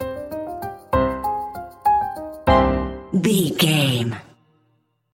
Uplifting
Aeolian/Minor
flute
oboe
strings
circus
goofy
comical
cheerful
perky
Light hearted
quirky